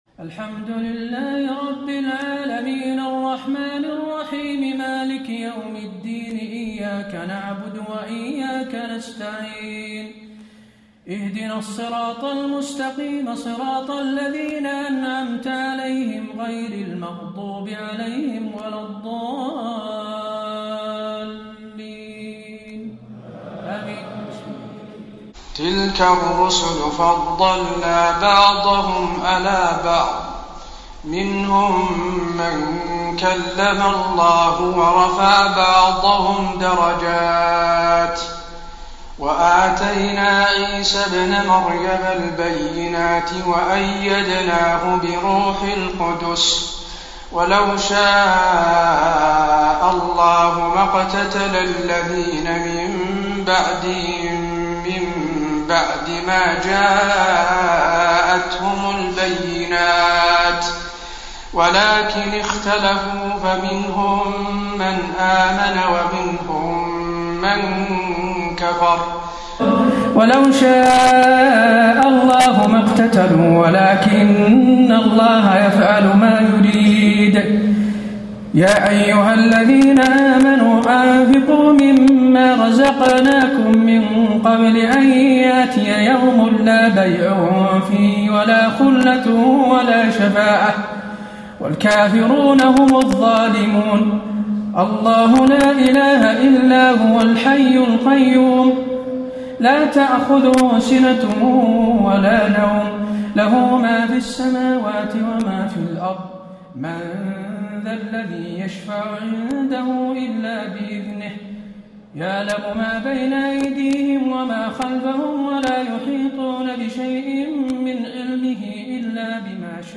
تهجد ليلة 23 رمضان 1435هـ من سورتي البقرة (253-286) و آل عمران (1-32) Tahajjud 23 st night Ramadan 1435H from Surah Al-Baqara and Aal-i-Imraan > تراويح الحرم النبوي عام 1435 🕌 > التراويح - تلاوات الحرمين